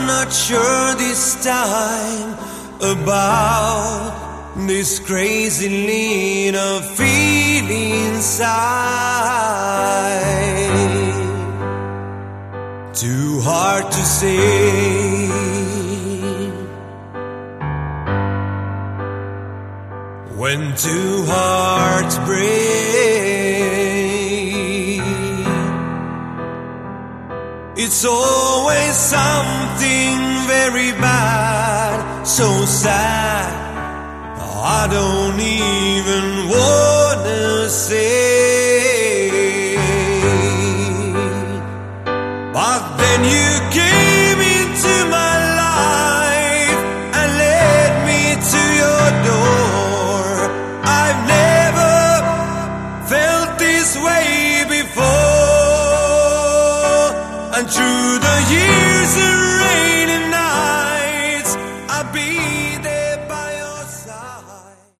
Category: Melodic Hard Rock
Guitar, Vocals
Drums
Bass
Keyboards, Vocals